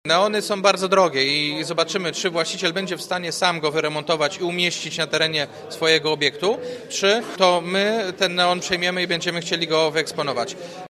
Miasto jest w stałym kontakcie z nowym właścicielem budynku, który w ramach jego rewitalizacji, założył także remont neonu. Mówi prezydent Jacek Wójcicki.